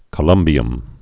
(kə-lŭmbē-əm)